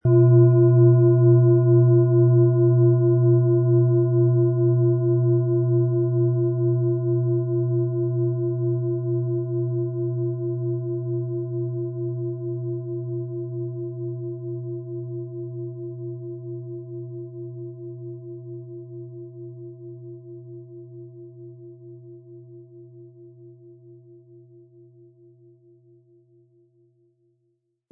Planetenschale®
• Mittlerer Ton: Chiron
• Höchster Ton: Mond
PlanetentöneLilith & Chiron & Mond (Höchster Ton)
MaterialBronze